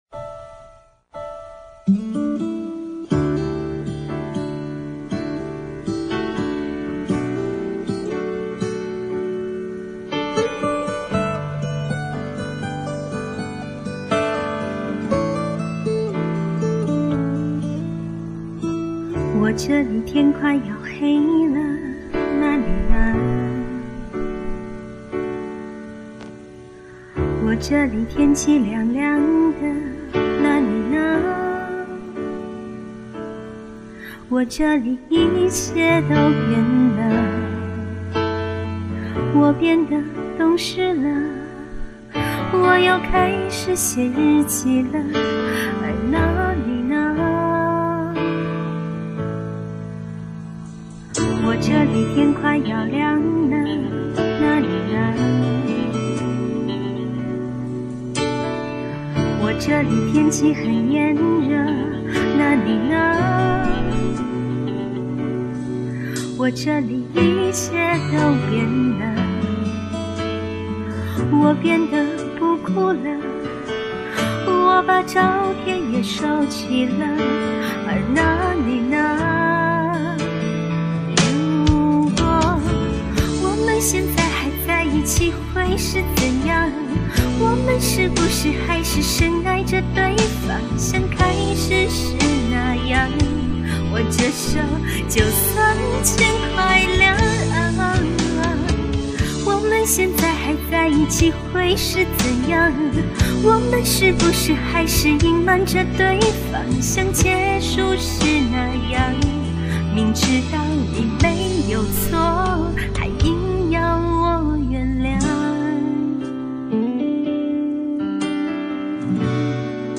制作版非现场
以前印象中最突出的地方是空灵